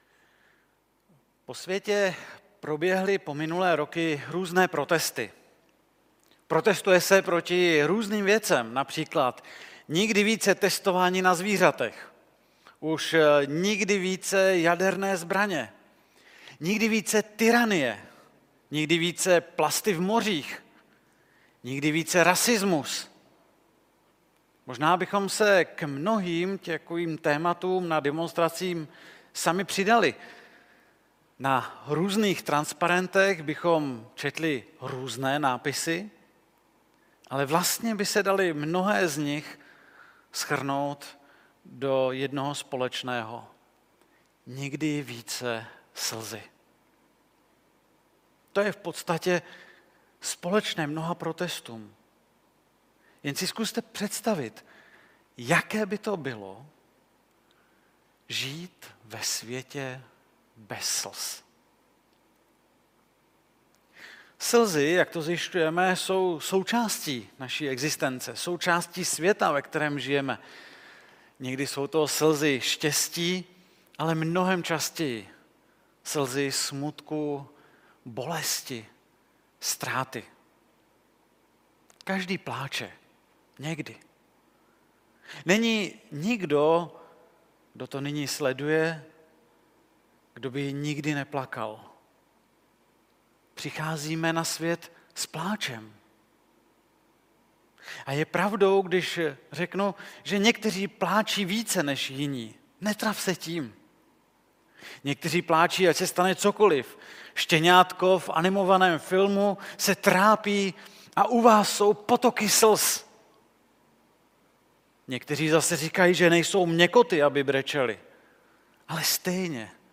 1. díl ze série kázání Nikdy více... (Žalm 13)